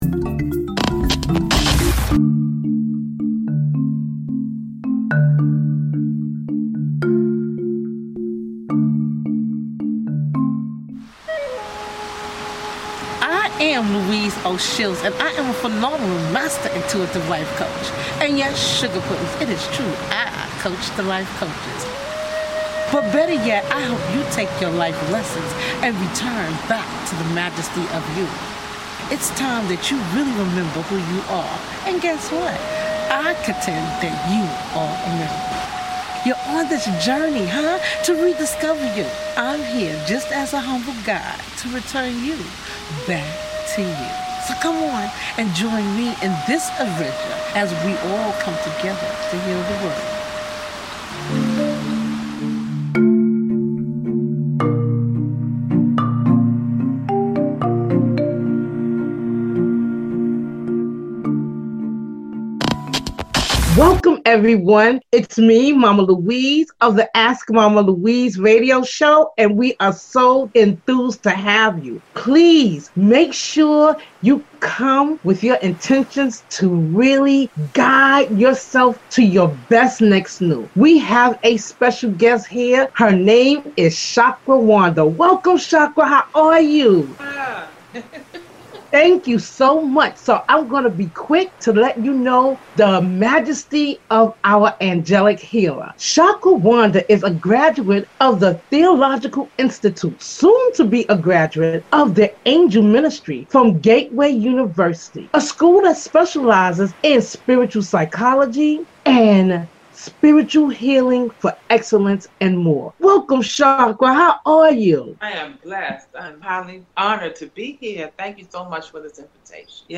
Genres: Talk Show, Spiritual, Spiritual growth, Angelic Healer, Human Design